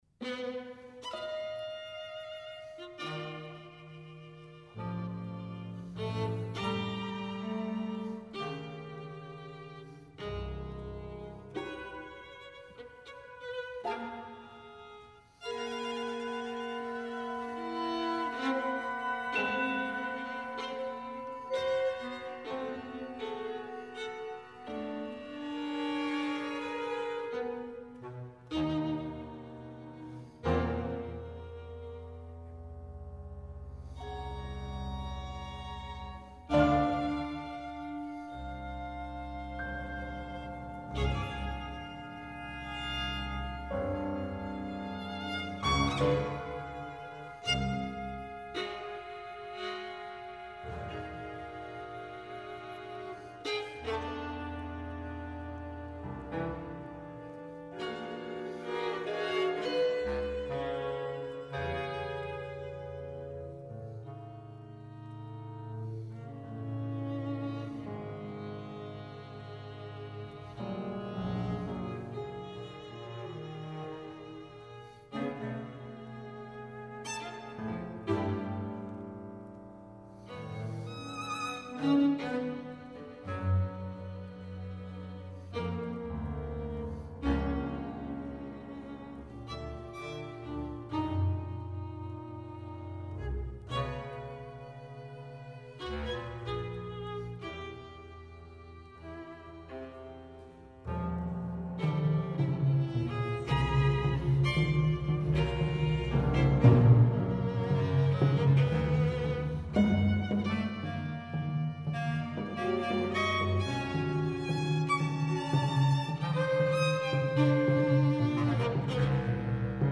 solo piano work